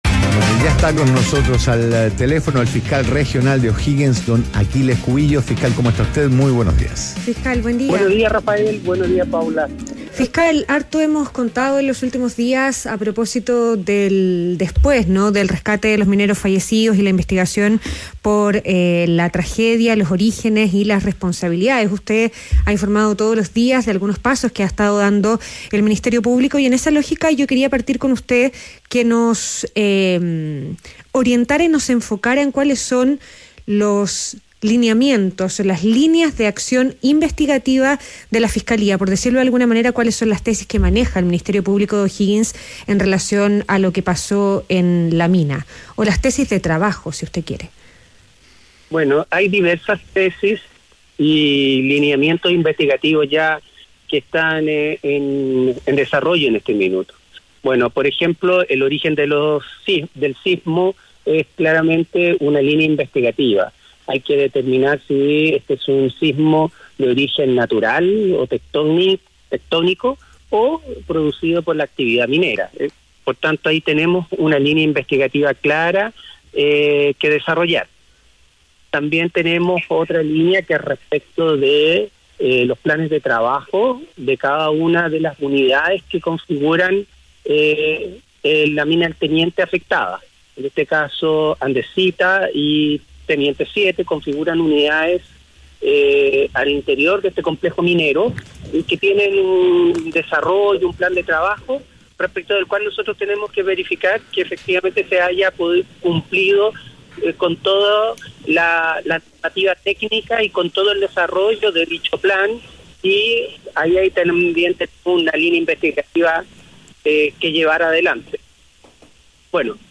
ADN Hoy - Entrevista a Aquiles Cubillos, fiscal regional de O'Higgins